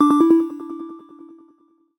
HTC Bildirim Sesleri
Ses çözünürlüğü 192 Kbps / 16 bits ve ses örnekleme oranı 48 Khz olarak üretilen sesler stereo ve yüksek ses çıkışına sahiptir.